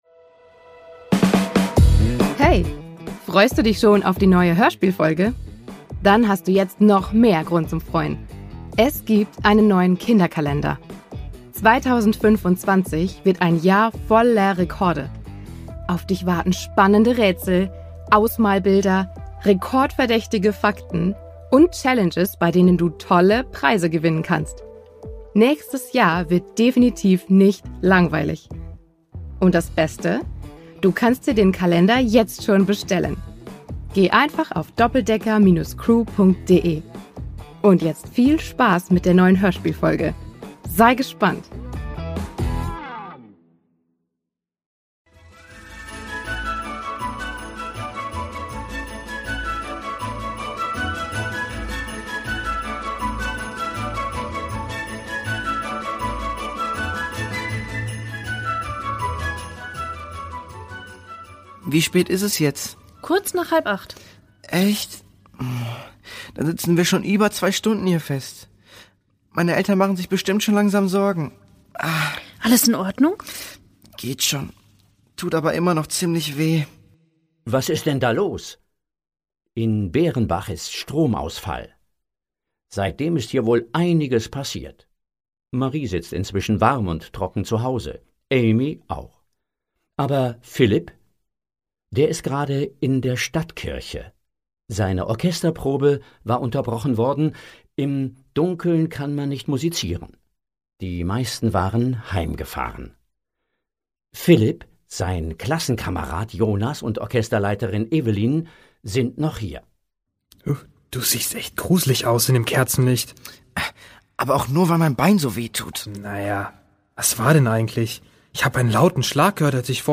Weihnachten 2: Mit Pauken und Trompeten … | Die Doppeldecker Crew | Hörspiel für Kinder (Hörbuch) ~ Die Doppeldecker Crew | Hörspiel für Kinder (Hörbuch) Podcast